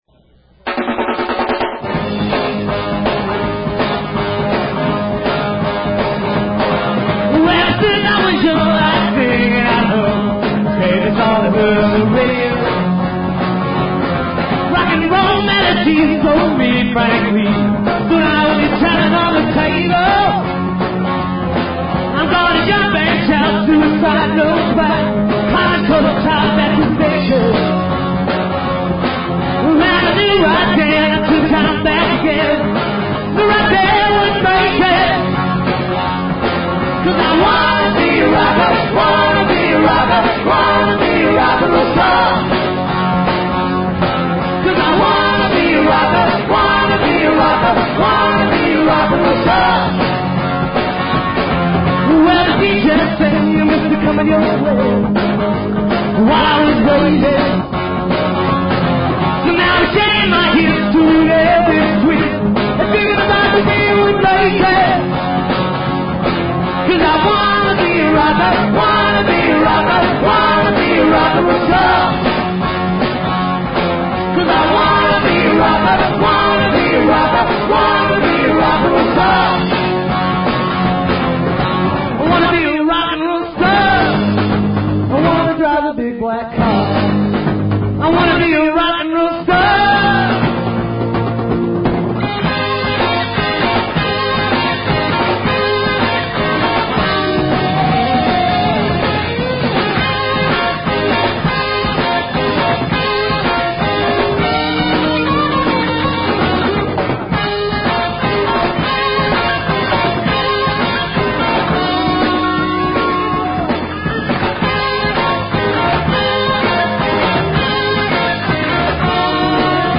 What a rare find for me. I dug up some old cassettes !